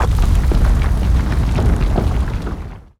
Exploding sound planet
A_SFX_Planet_Explosion_01.wav